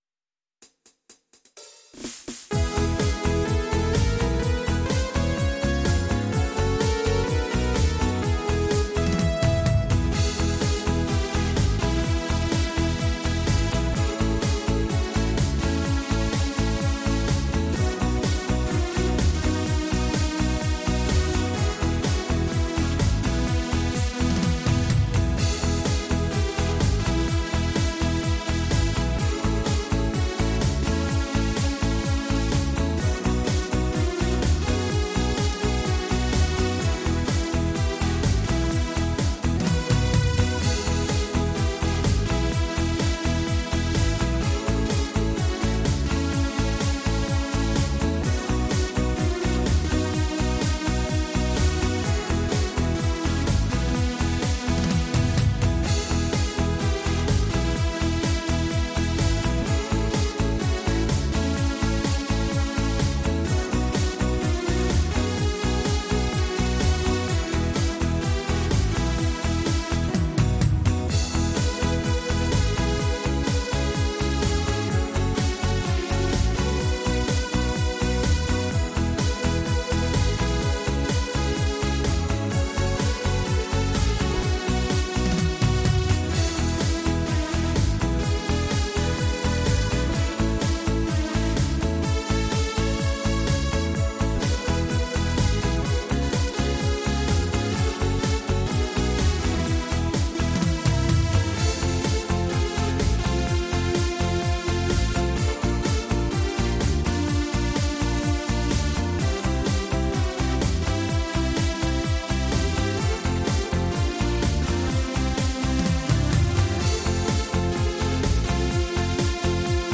Disco-Fox